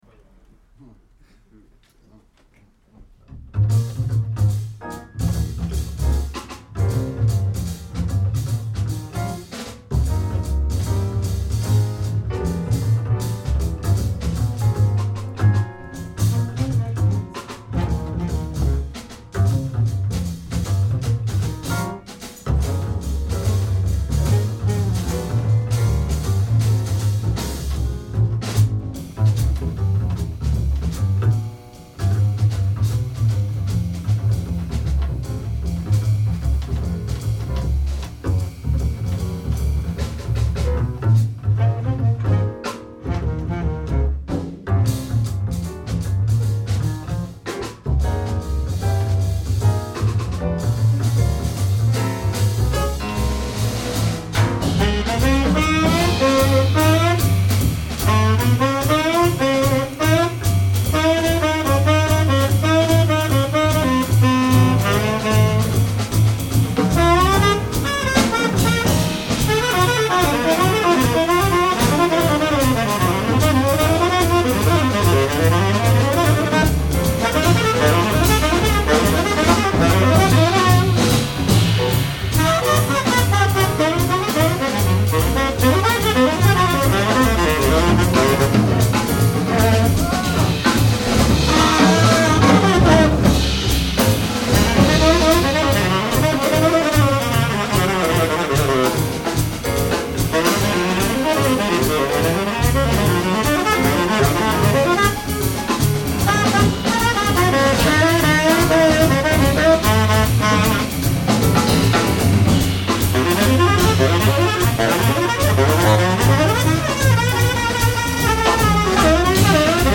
Beginning of the Second Set
Bass
Piano
Drums
Tenor Sax